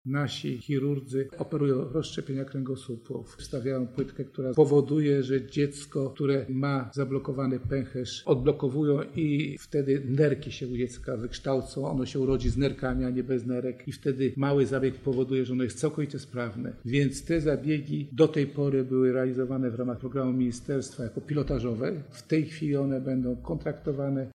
– mówi Krzysztof Michałkiewicz, Sekretarz Stanu w Ministerstwie Rodziny Pracy i Polityki Społecznej.